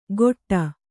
♪ goṭṭa